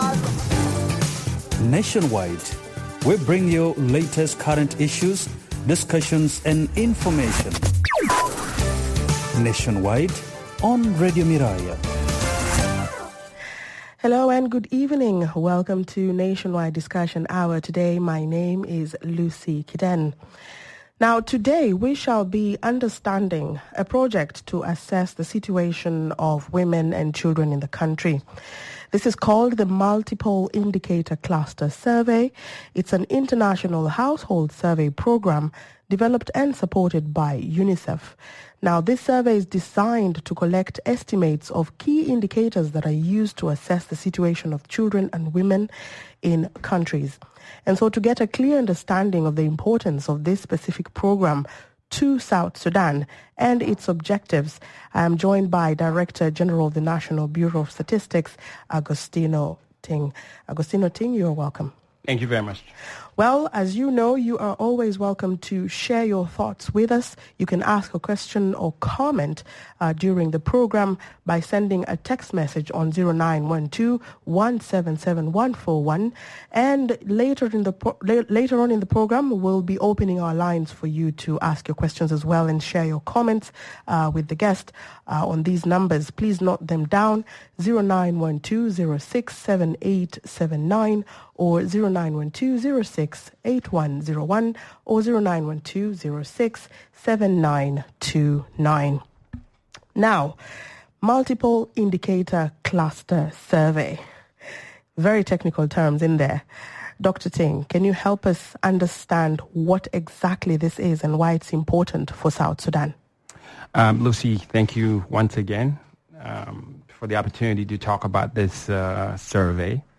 Headliner Embed Embed code See more options Share Facebook X Subscribe Guests: - Augustino Ting, Director General of the National Bureau of Statistics Share Facebook X Subscribe Next NW.